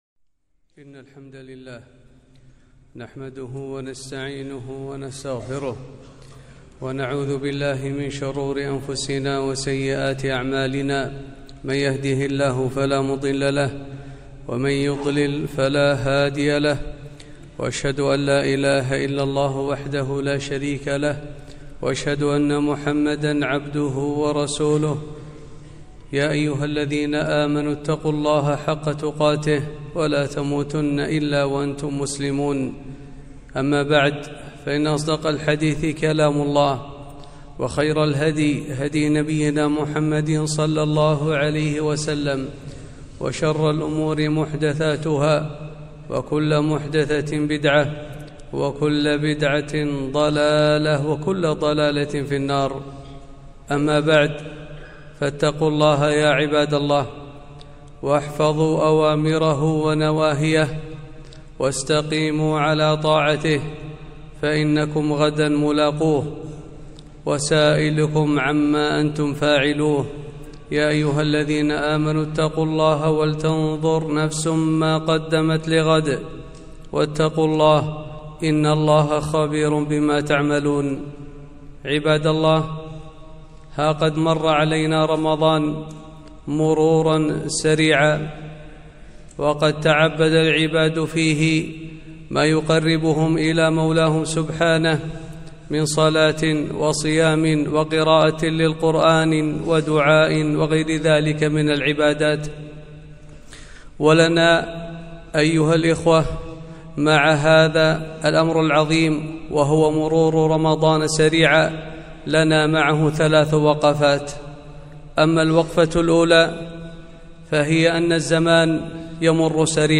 خطبة - وقفات بعد رمضان